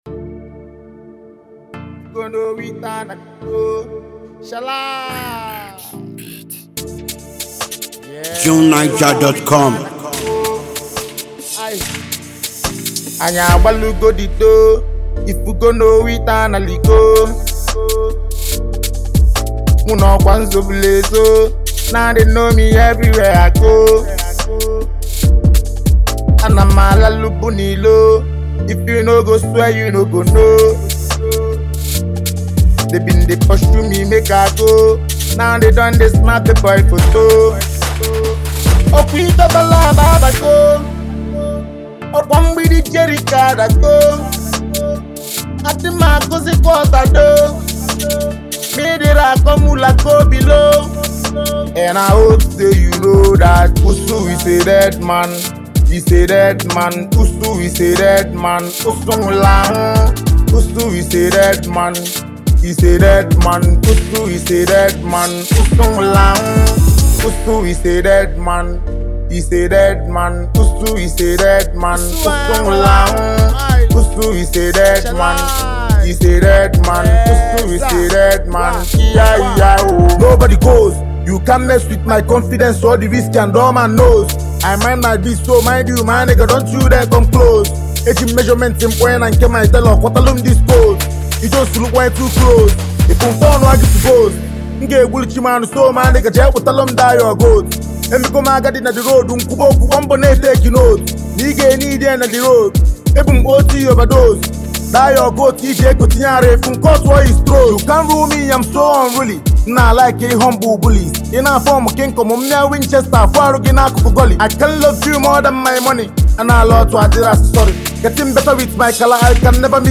Nigerian rap musician